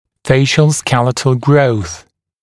[‘feɪʃ(ə)l ‘skelɪt(ə)l grəuθ][‘фэйш(э)л ‘скэлит(э)л гроус]рост лицевого отдела черепа